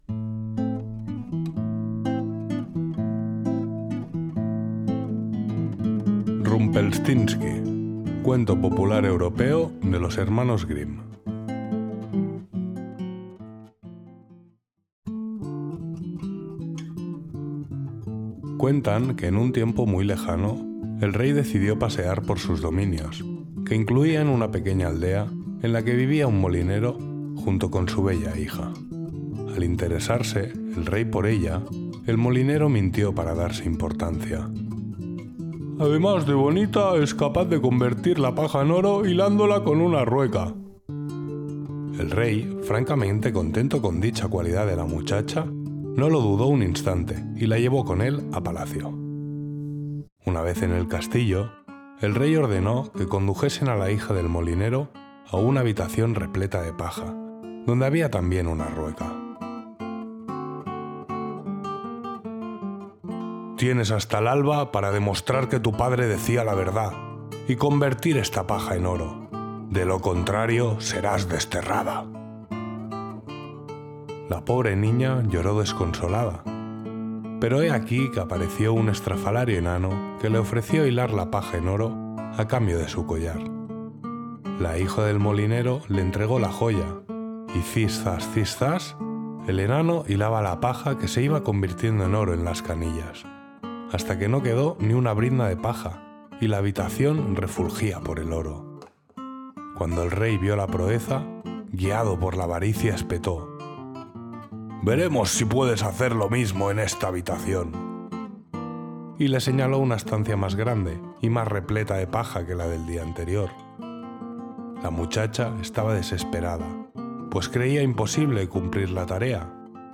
Cuento narrado Rumpelstiltskin de los Hermanos Grimm